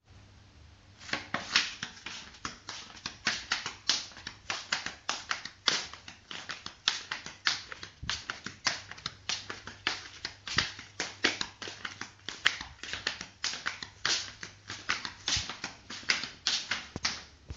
描述：模仿步行动画和卡通项目
标签： 脚步 台阶 走路 地板
声道立体声